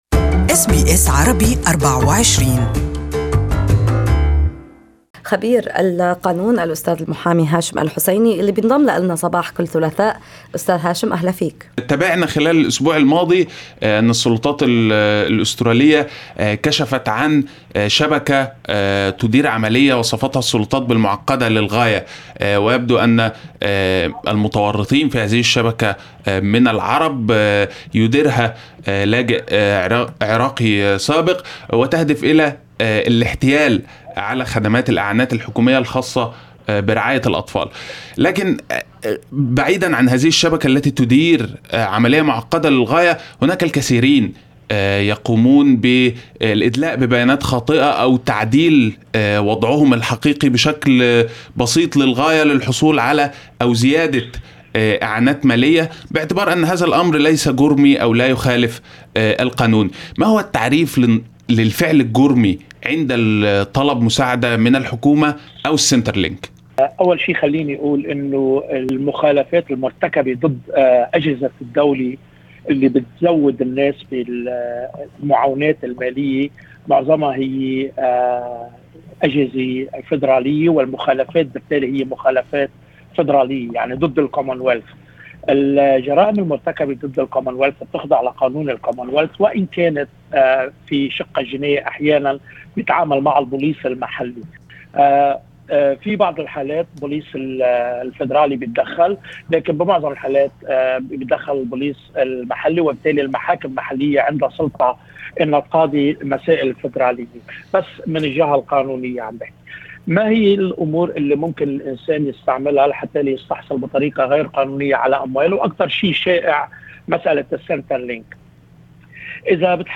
اللقاء